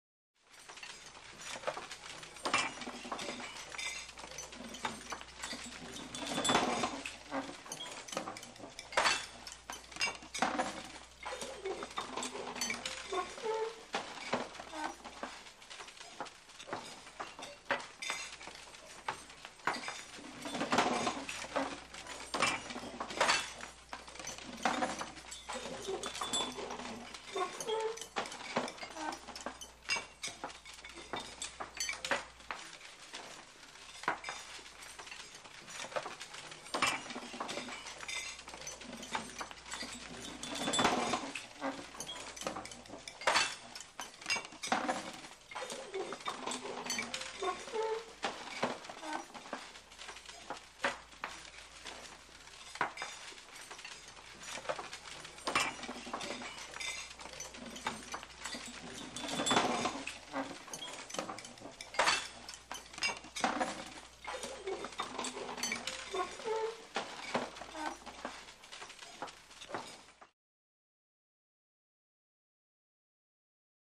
Restaurant - Italian Restaurant, Walla